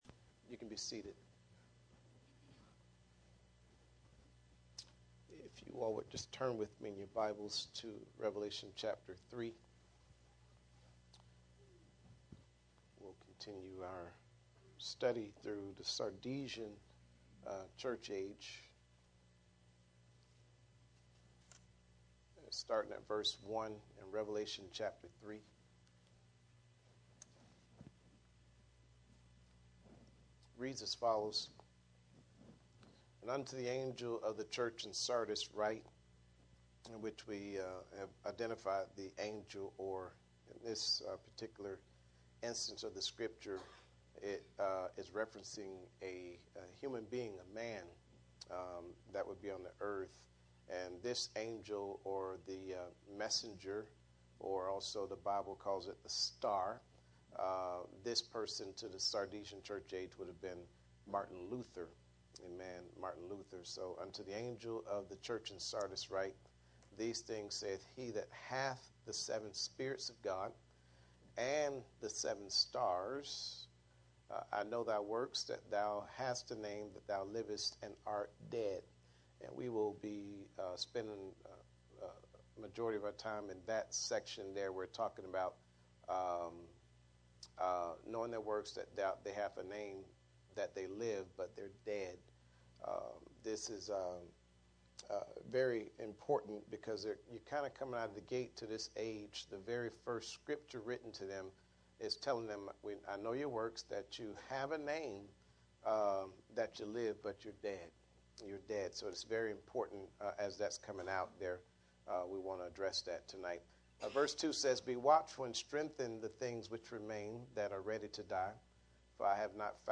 Series: Bible Study Passage: Revelation 3:1-6 Service Type: Midweek Meeting %todo_render% « The Anointing Makes The Difference He Conquered